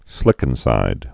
(slĭkən-sīd)